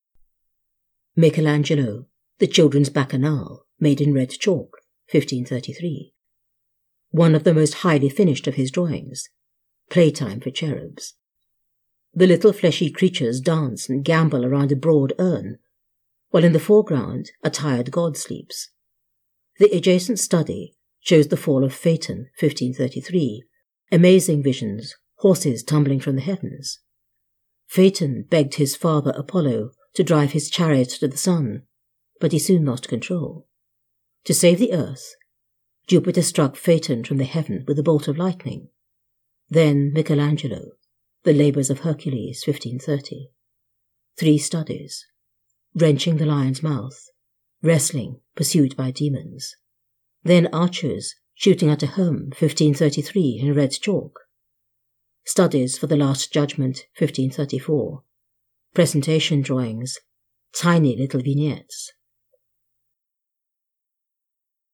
The Bill Viola/Michelangelo audiobook is available on Amazon, Audible and iTunes.